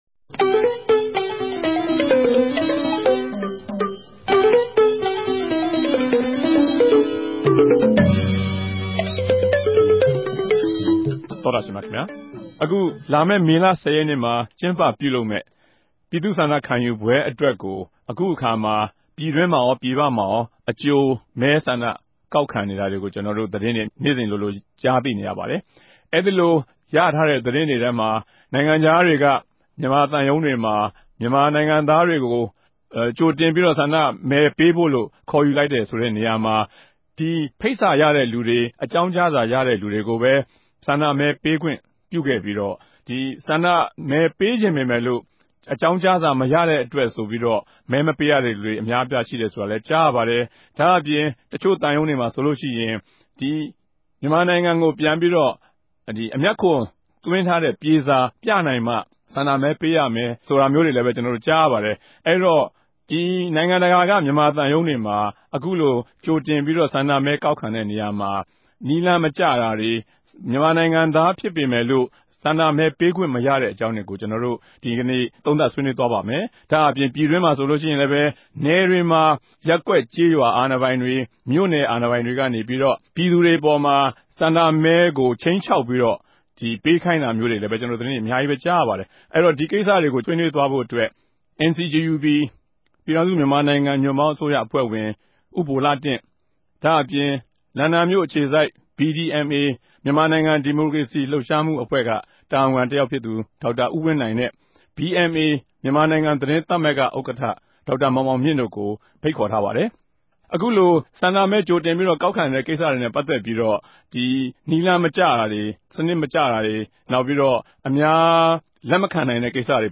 တနဂဿေိံြ ဆြေးေိံြးပြဲ စကားဝိုင်း။